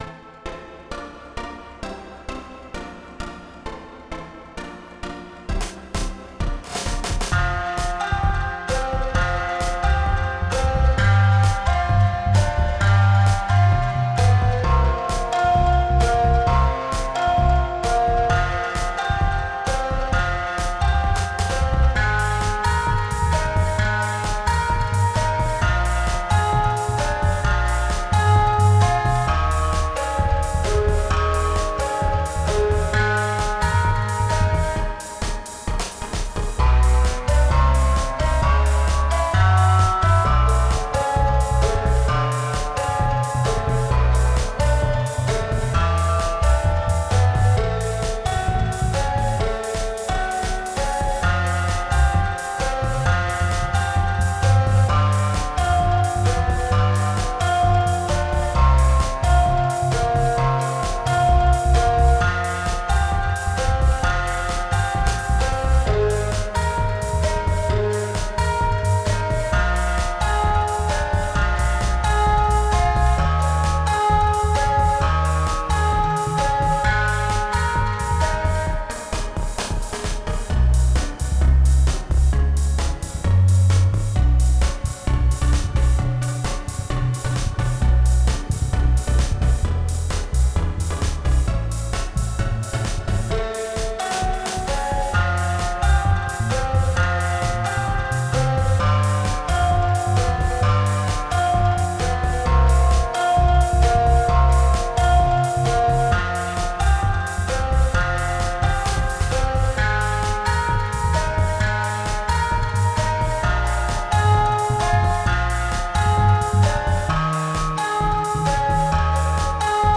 Electric Bass, Techno Drums, Synths.
Genre: Hip-Hop